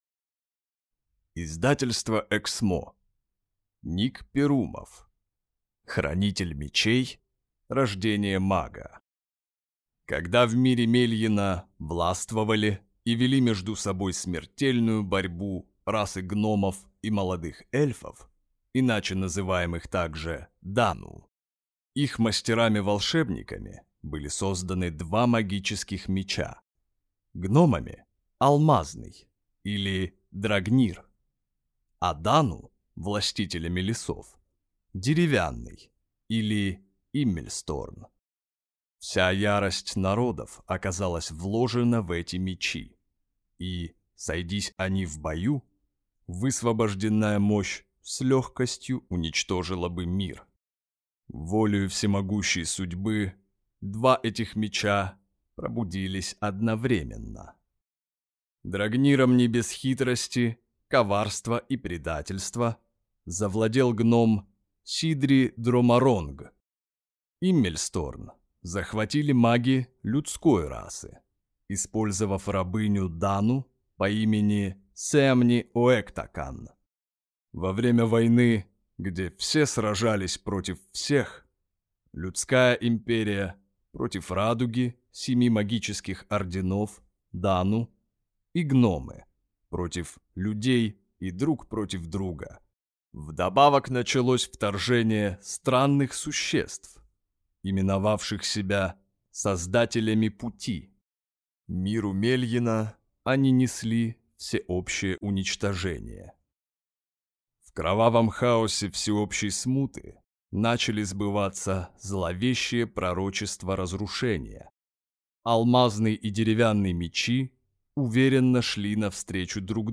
Аудиокнига Хранитель Мечей. Рождение Мага | Библиотека аудиокниг